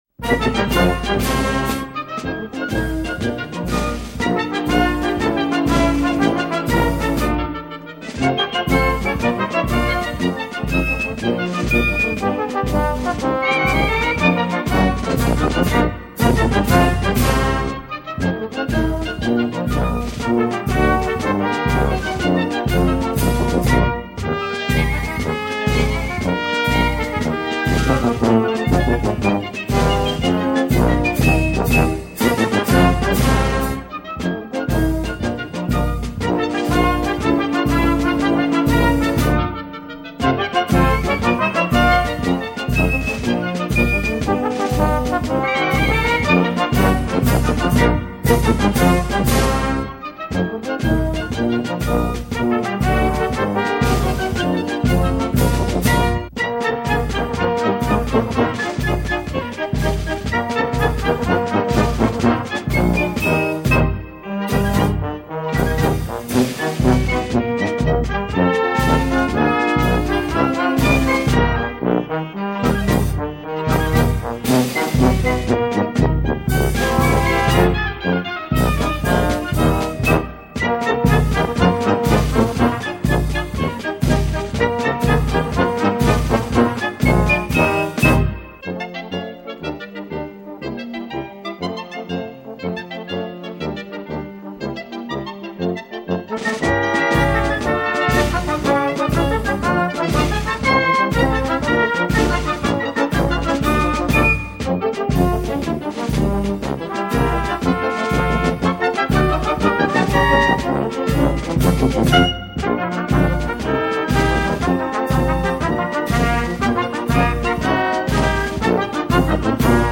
Escuta 2.  Uma noite na folia. Quadrilha.mp3